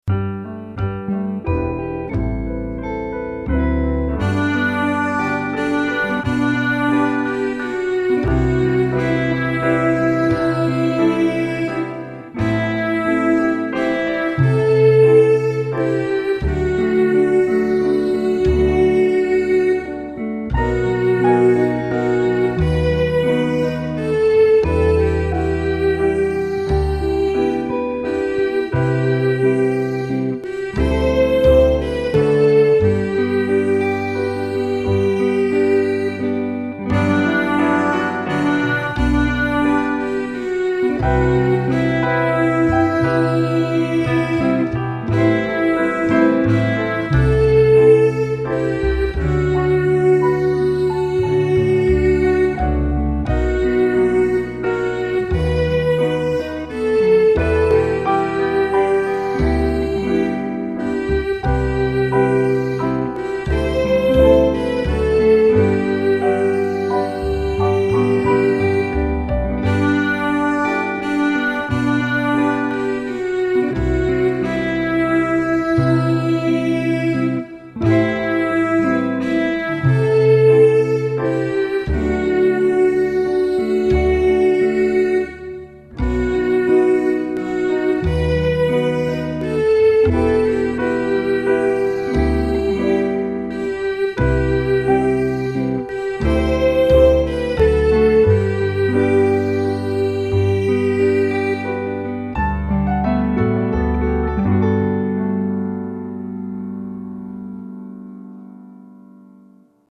Eucharist chant